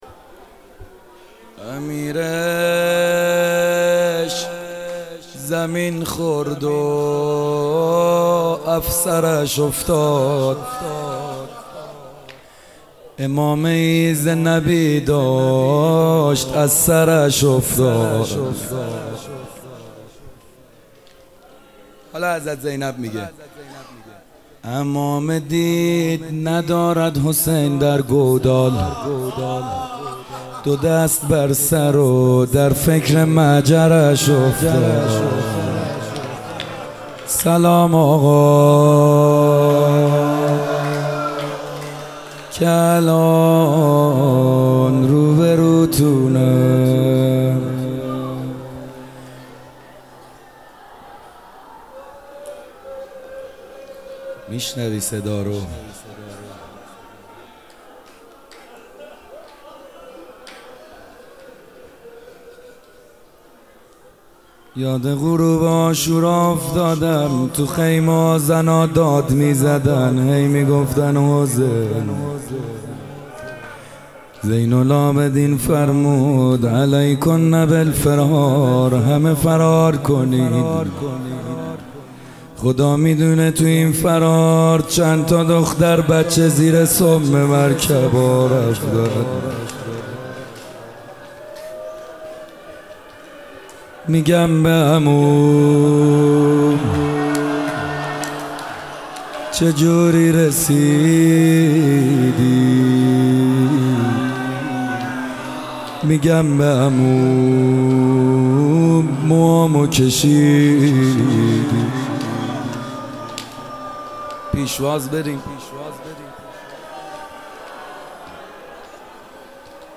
محرم98 - شب دوم - روضه پایانی
مهدیه امام حسن مجتبی(ع)